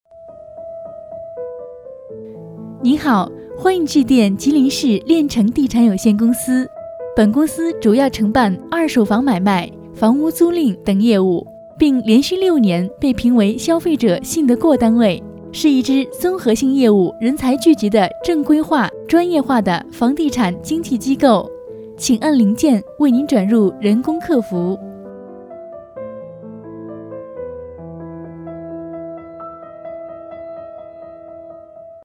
女26 地产公司.mp3